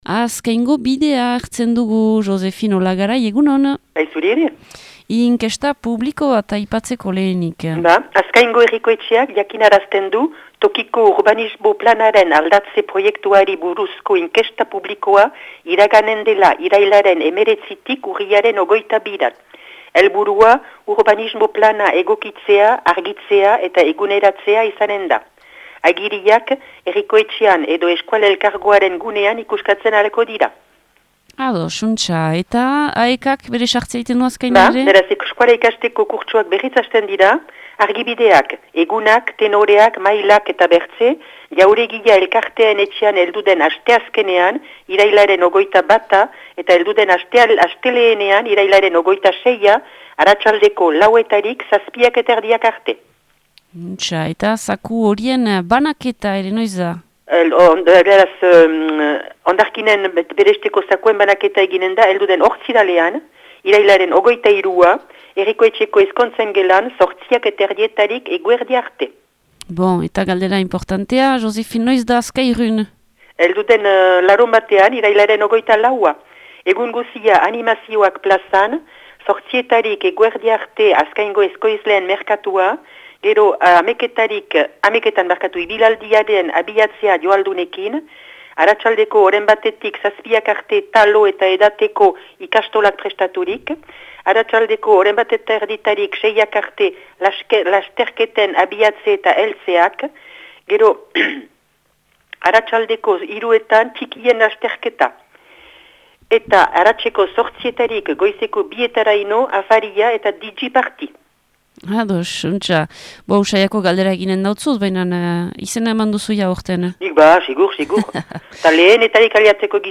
BERRIKETARIAK | AZKAINE